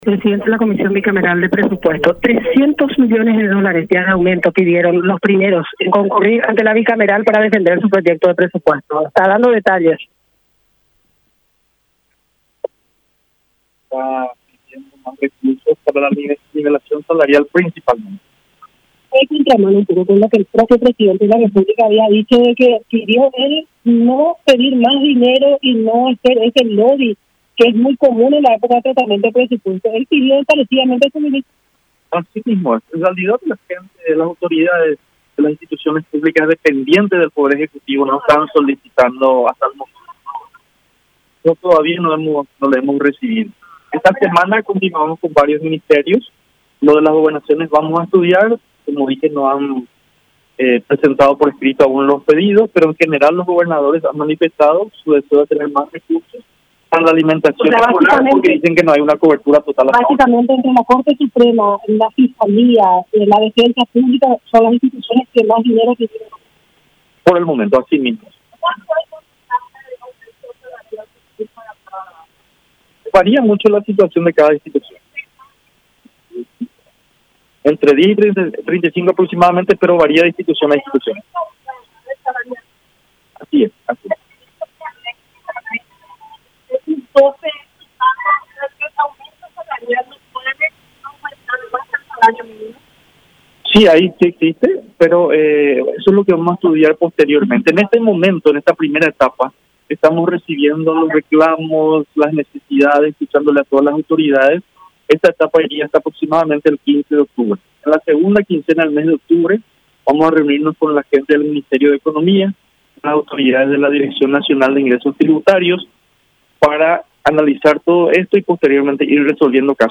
“En realidad las autoridades de las instituciones públicas dependientes del Poder Ejecutivo nos están solicitando hasta el momento, pero todavía no hemos recibido”, declaró Derlis Osorio presidente de la Bicameral de Presupuesto.